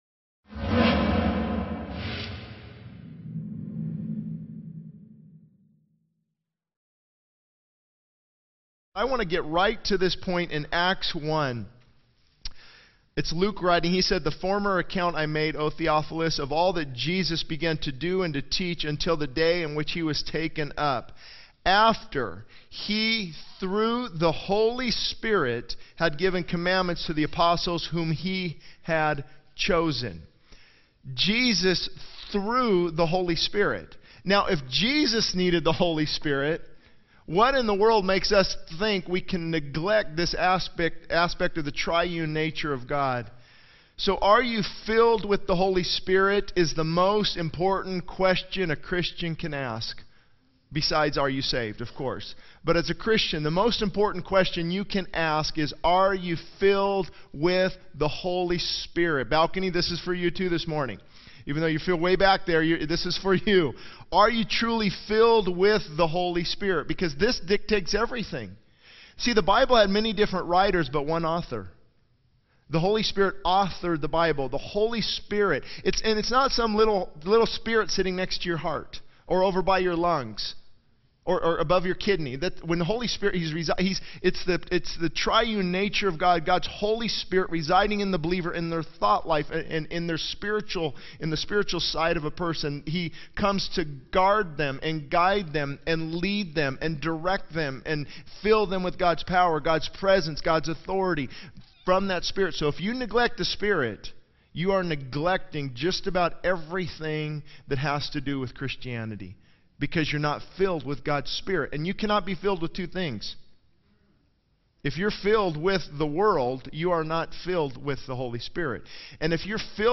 This sermon emphasizes the importance of being filled with the Holy Spirit, drawing parallels to Jesus' obedience to the Holy Spirit and the need for believers to seek, ask, submit, and obey to experience the Spirit's power. It highlights the necessity of humility, seeking God's presence, and the transformation that occurs when one is truly filled with the Spirit, impacting every aspect of life.